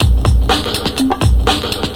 98 Bpm Drum Loop Sample A# Key.wav
Free drum loop sample - kick tuned to the A# note.
98-bpm-drum-loop-sample-a-sharp-key-yRW.ogg